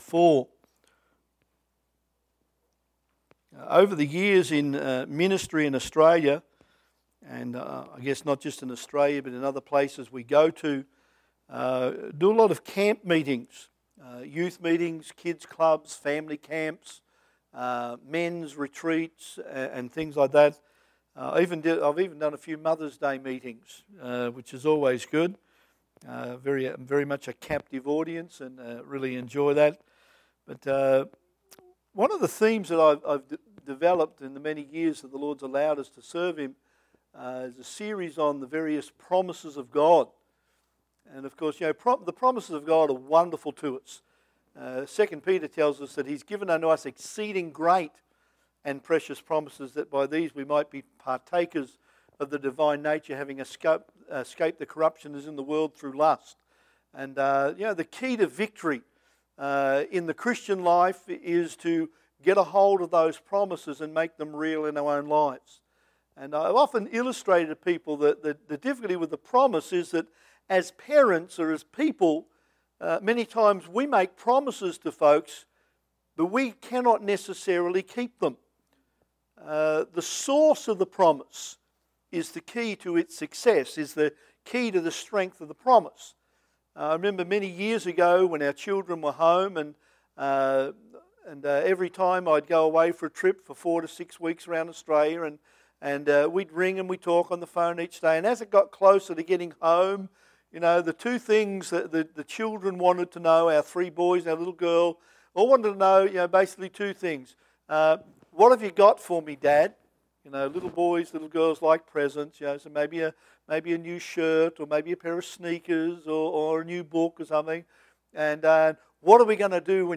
Missions Conference 2019 Service Type: Sunday AM Preacher